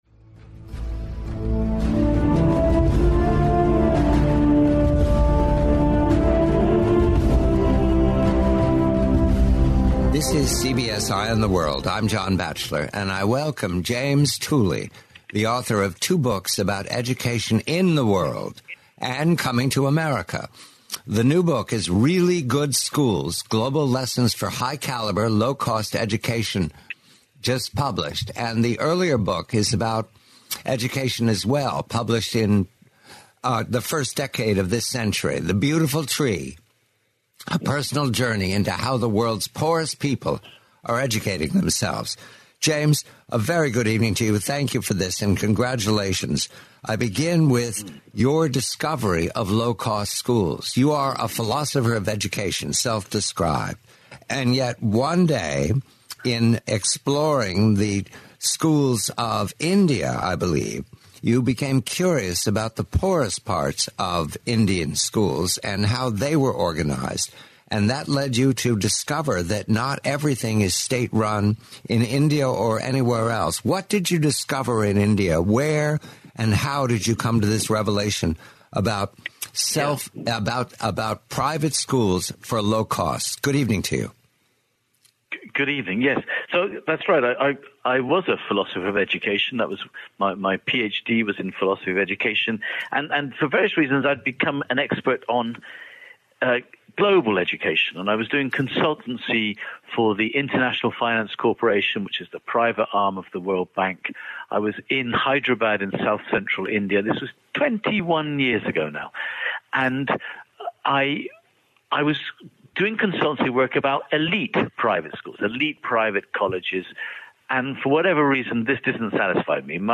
the complete, forty-minute interview.